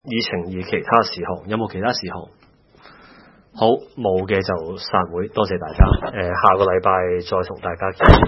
區議會大會的錄音記錄